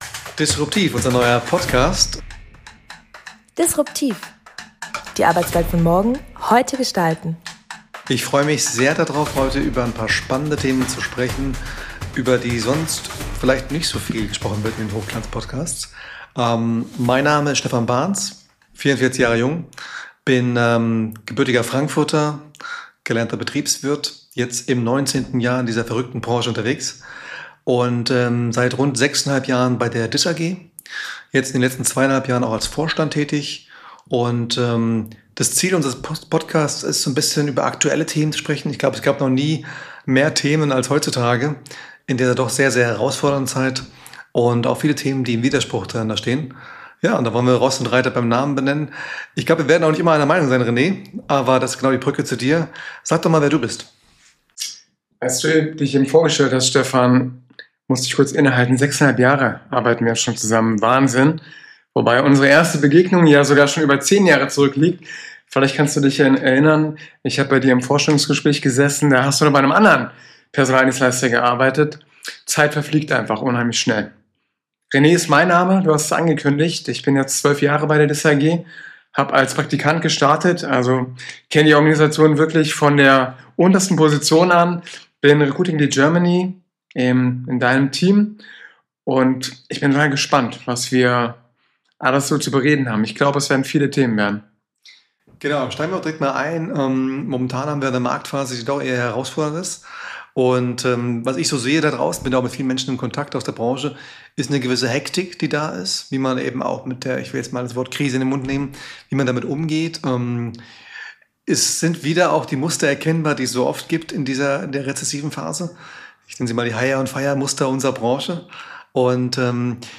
Beschreibung vor 5 Monaten Was passiert, wenn zwei Brancheninsider offen über Themen sprechen, die viele lieber meiden?